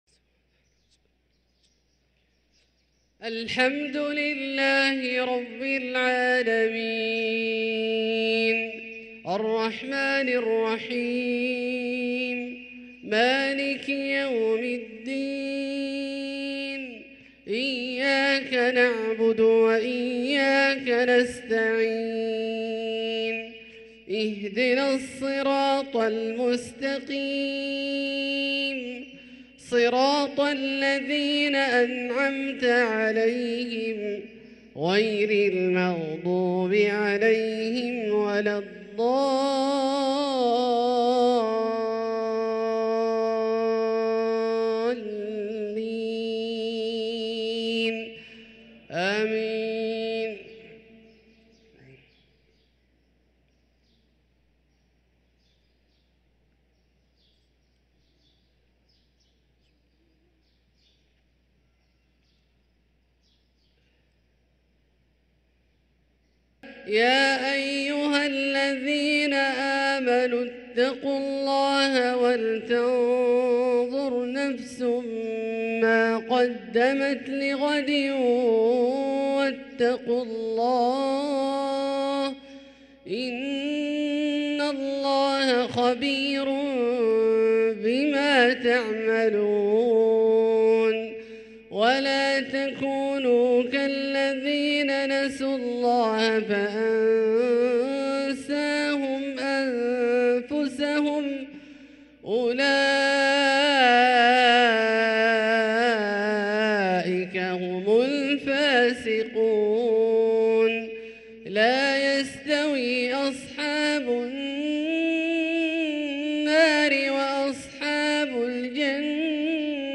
صلاة المغرب للقارئ عبدالله الجهني 7 رجب 1444 هـ